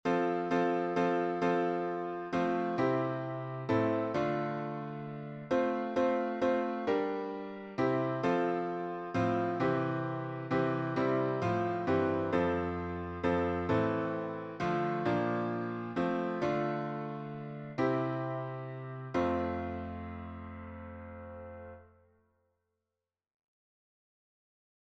A cappella
SATB